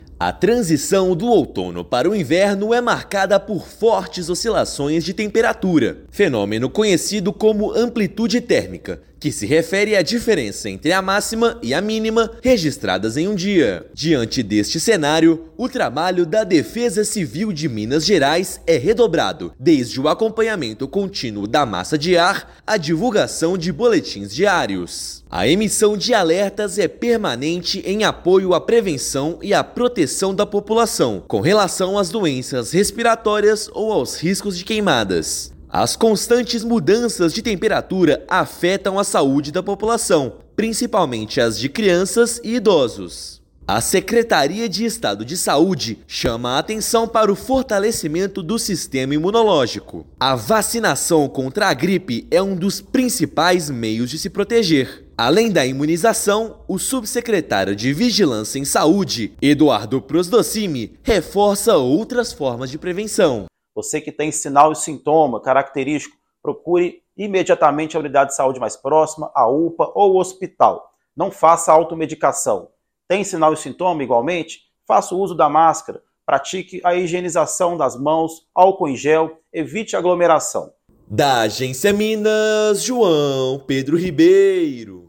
Com outono típico e previsão de tempo seco no inverno, população deve redobrar cuidados com a saúde e o meio ambiente. Ouça matéria de rádio.